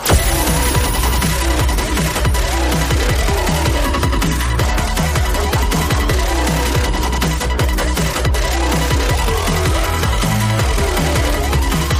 Big_Win_Sound.mp3